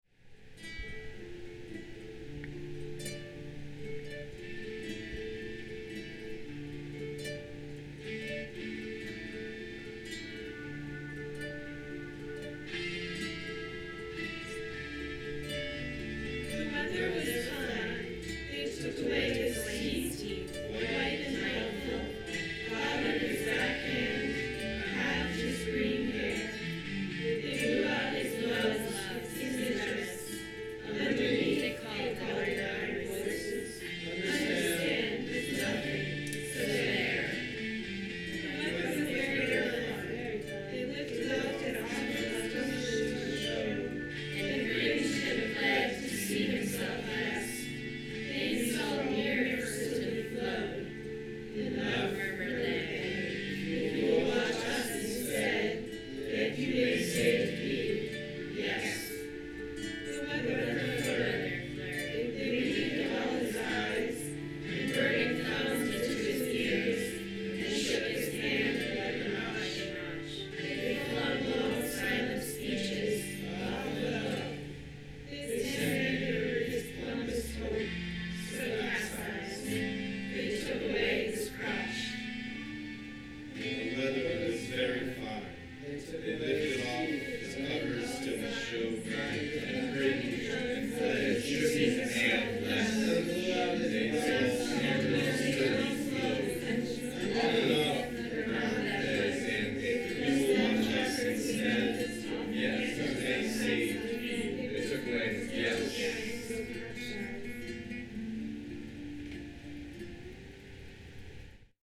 Each of these versions of the poem contains a fourth stanza section in addition to the three that the poem properly contains: a stanza of all the stanzas stacked on top of one another, and recited simultaneously.
(A) A cacophonous chorus:
Recorded on Saturday, January 29, at the Baltimore Free School (Blue Room, 1323 N. Calvert) from 2-3:30 pm.